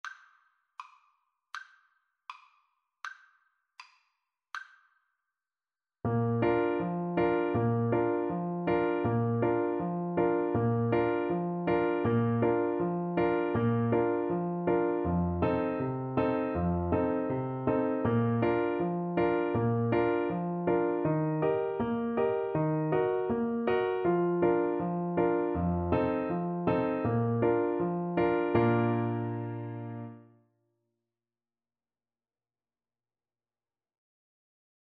Play (or use space bar on your keyboard) Pause Music Playalong - Piano Accompaniment Playalong Band Accompaniment not yet available reset tempo print settings full screen
Bb major (Sounding Pitch) F major (French Horn in F) (View more Bb major Music for French Horn )
Traditional (View more Traditional French Horn Music)